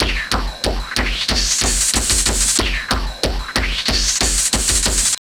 SWEEP STUFF 1.wav